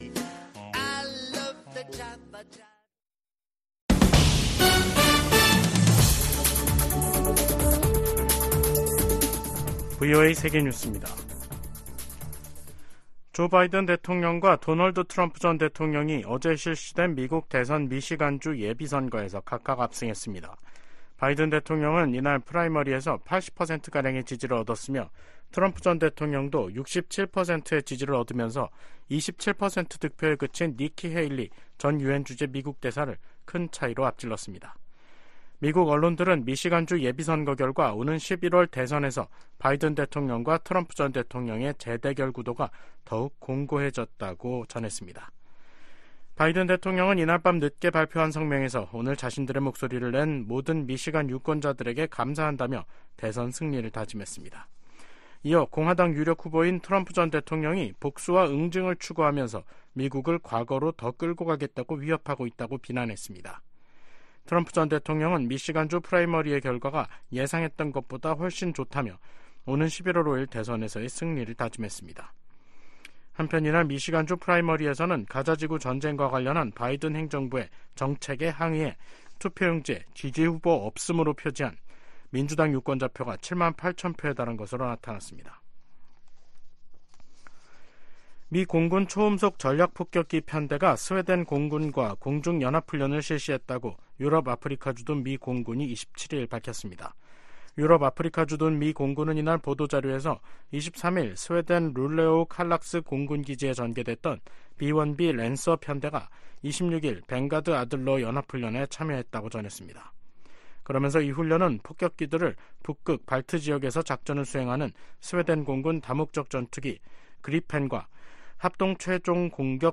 VOA 한국어 간판 뉴스 프로그램 '뉴스 투데이', 2024년 2월 28일 3부 방송입니다.